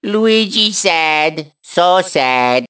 One of Luigi's voice clips in Mario Kart 7